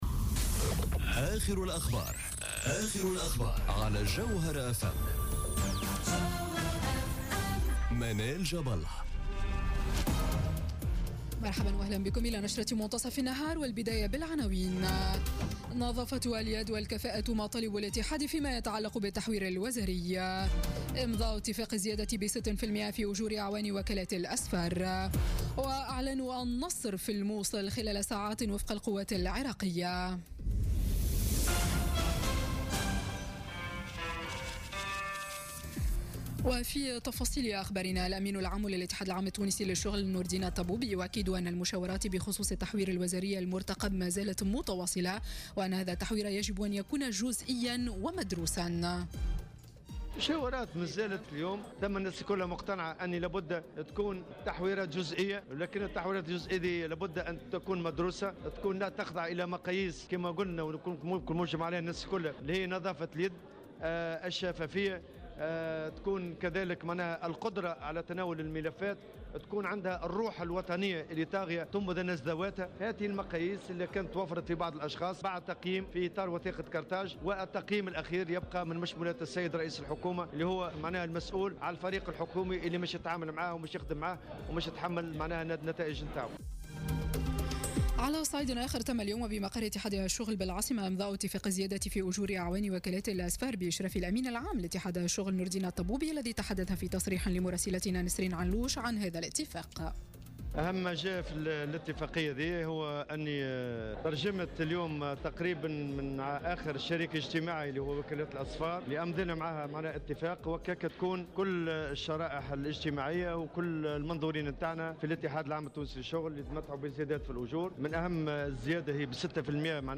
نشرة أخبار منتصف النهار ليوم السبت 08 جويلية 2017